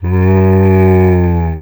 c_zombim2_hit3.wav